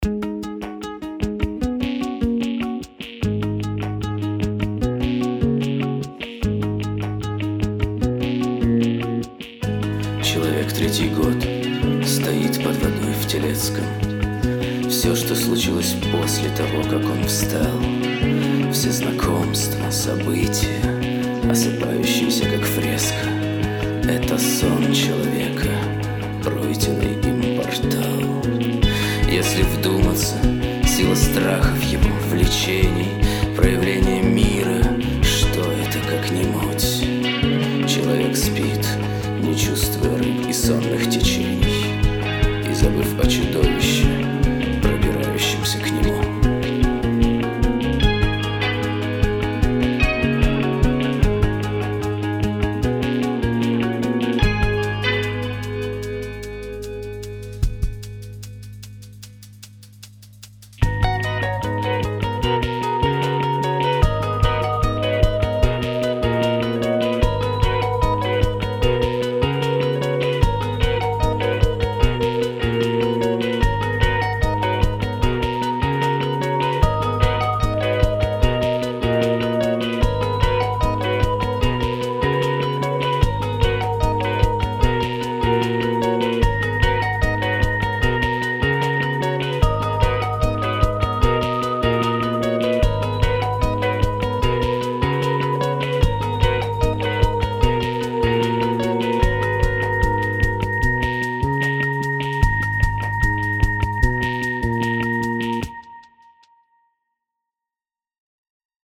Прошу оценить сведение [abstract instrumental poetry]